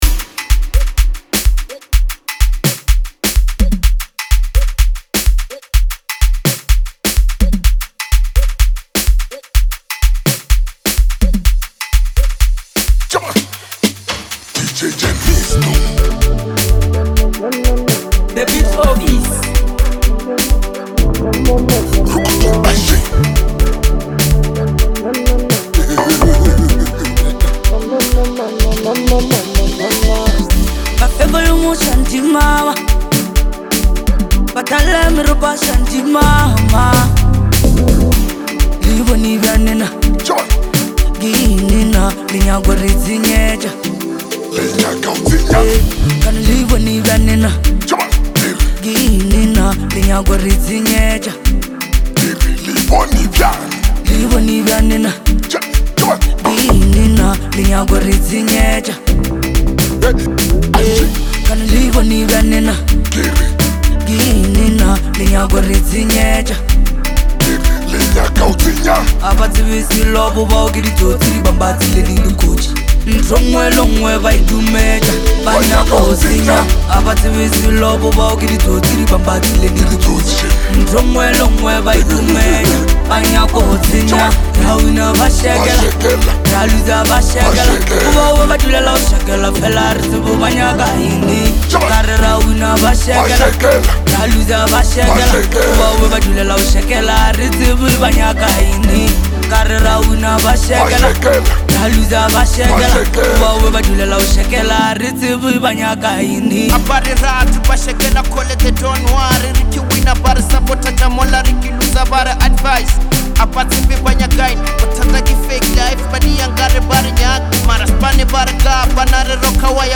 explosive energy,sharp creativity